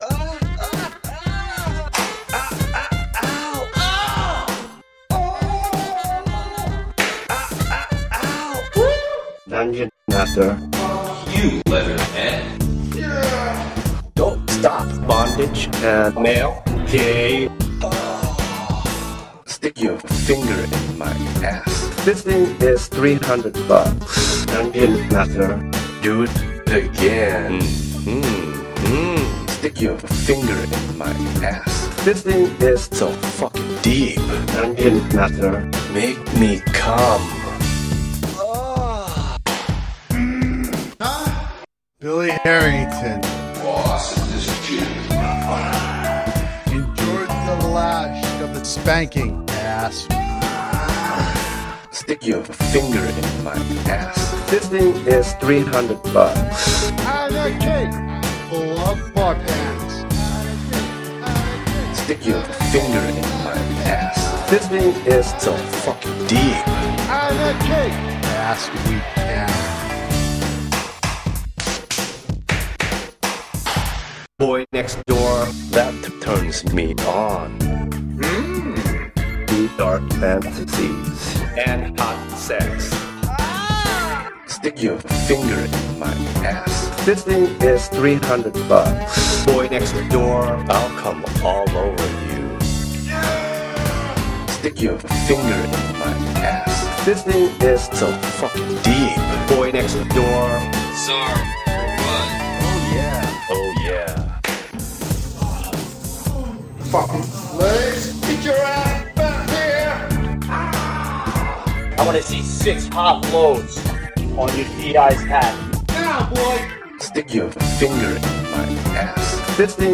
Гачи-ремиксы Делала я их в Ardour.